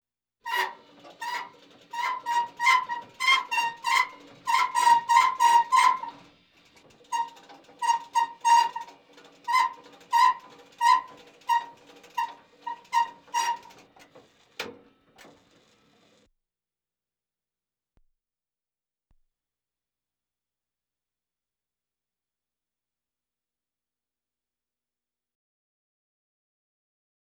transport
Tank M1 Main Gun Elevator Suppressor